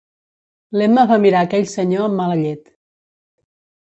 [ˈma.lə]